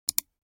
دانلود صدای موش 3 از ساعد نیوز با لینک مستقیم و کیفیت بالا
جلوه های صوتی